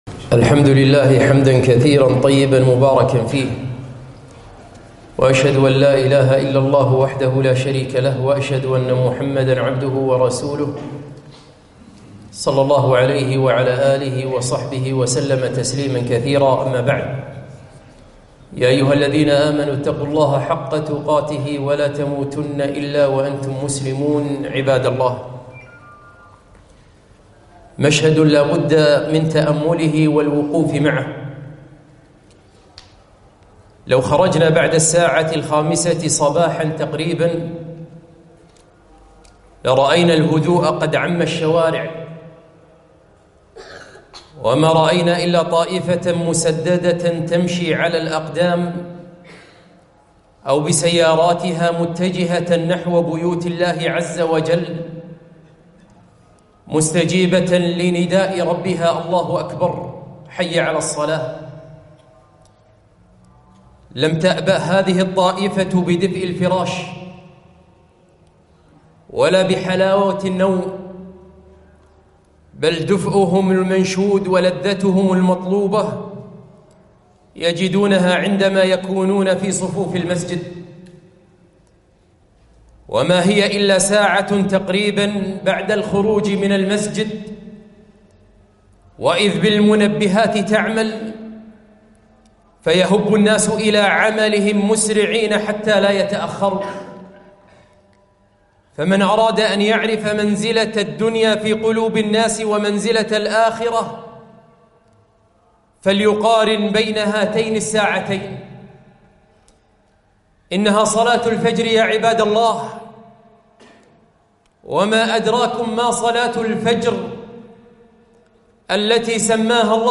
خطبة - المشاؤون في الظلمات